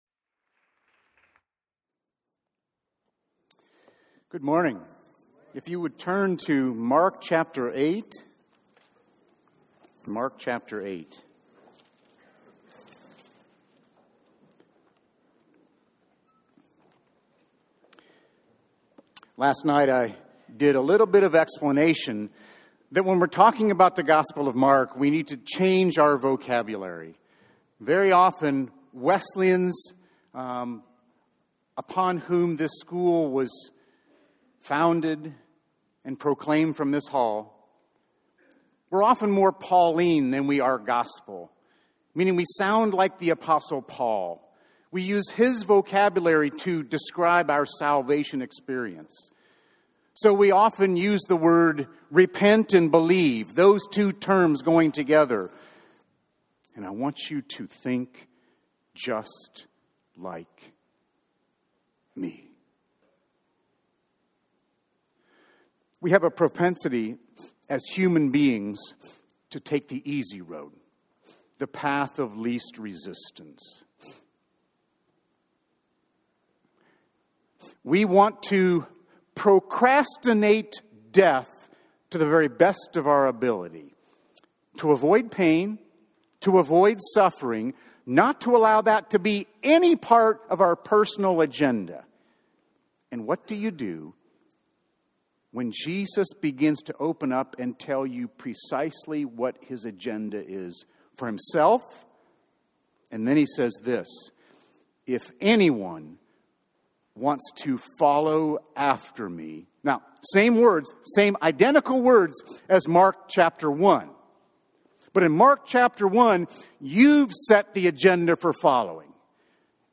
During Wednesday Chapel